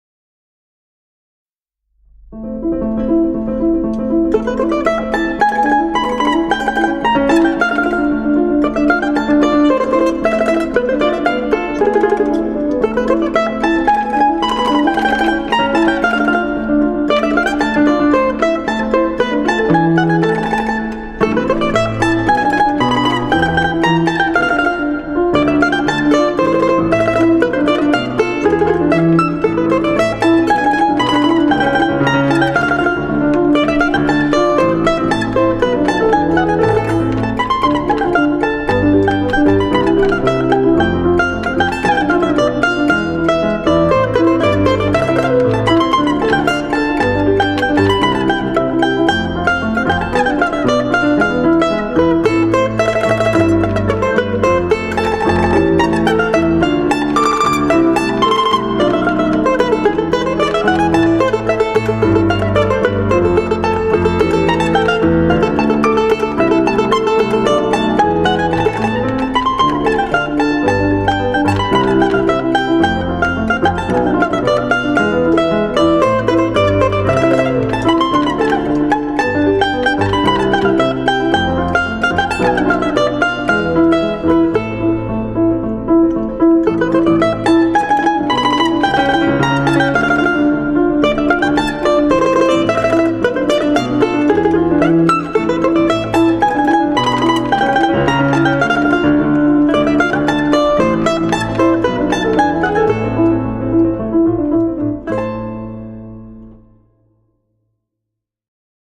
• Category: Domra